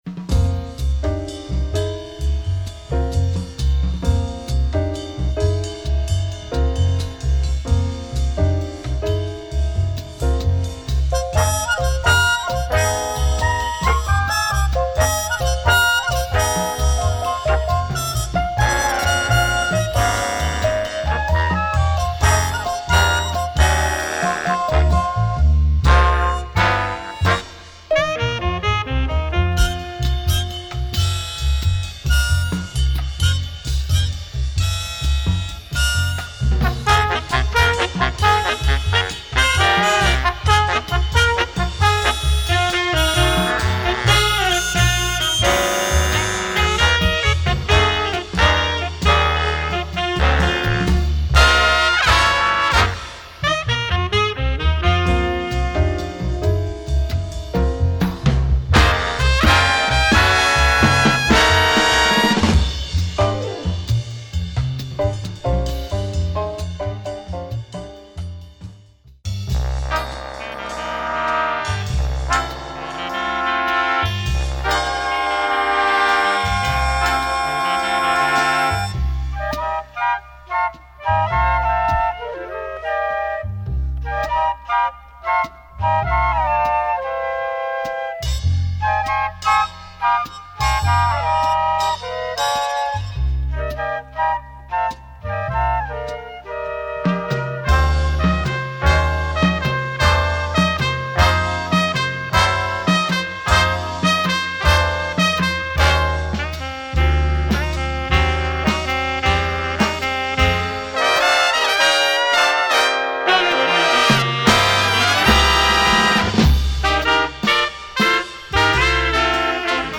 Beautiful and iconic Spanish jazz album